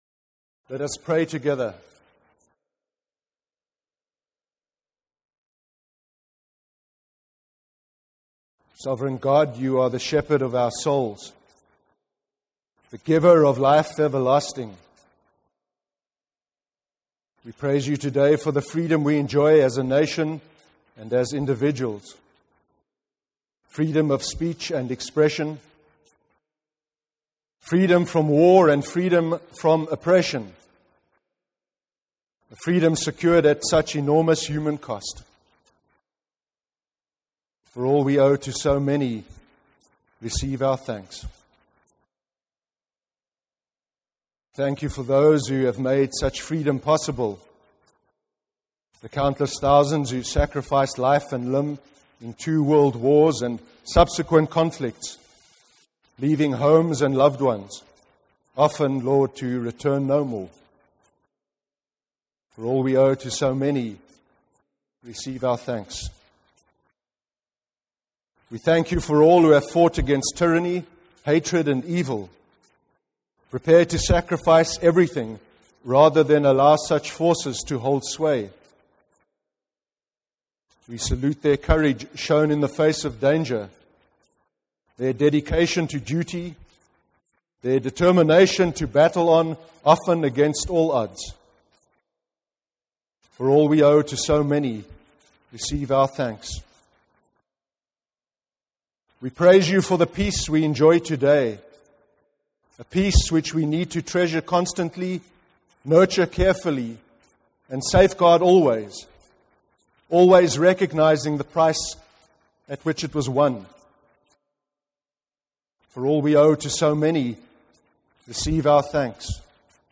09/11/2014 sermon – Remembrance Sunday sermon